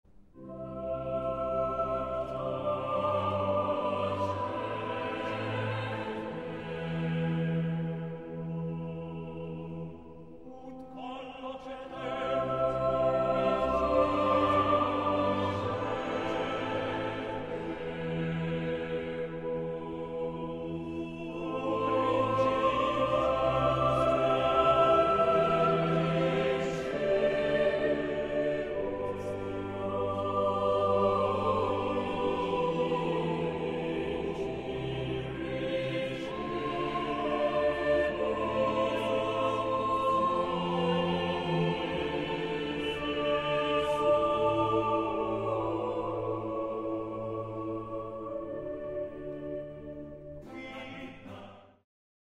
Nu niet meer de opzwepende ritmiek, maar netjes alles in een 4/4 maat.
We horen uitsluitend lange tonen, die via een dissonerende secunde iedere keer weer oplossen naar een terts.
Hemelse muziek!